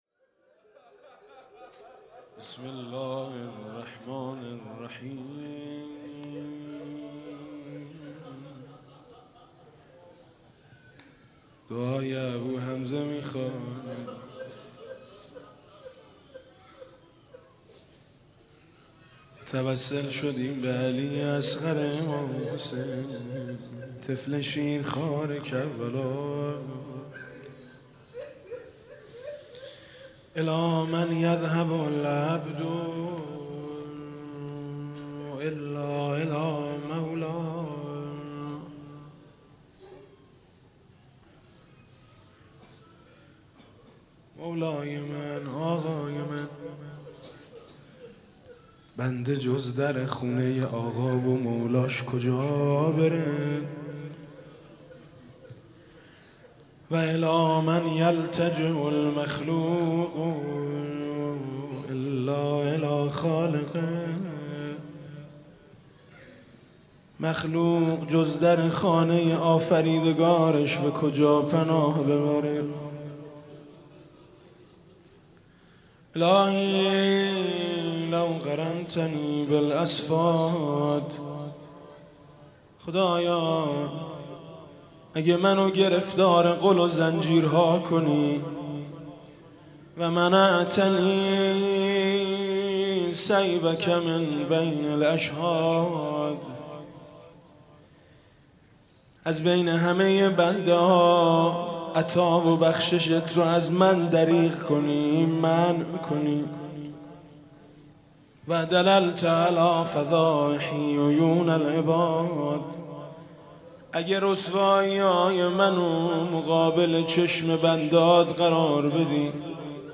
شب هیجدهم رمضان 96 - هیئت شهدای گمنام - فرازهایی از دعای ابوحمزه ثمالی